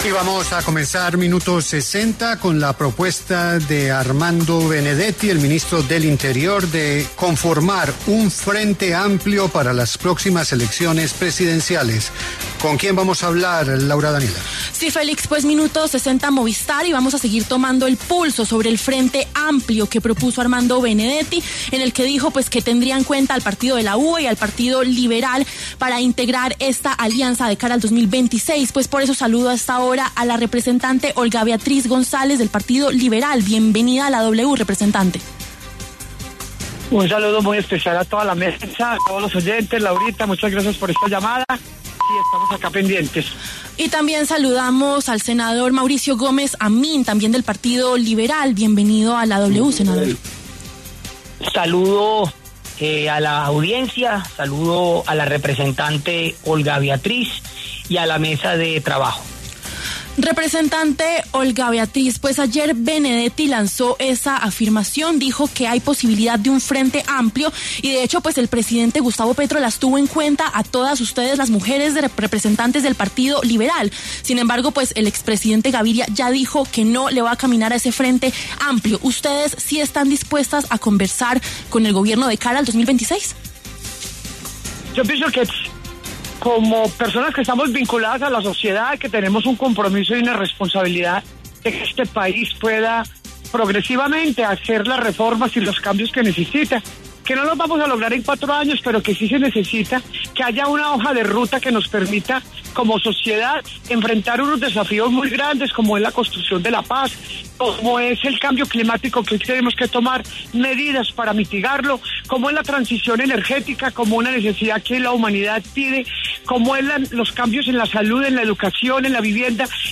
Sobre la propuesta opinaron la representante Olga Beatriz González y el senador Mauricio Gómez Amín en los micrófonos de La W.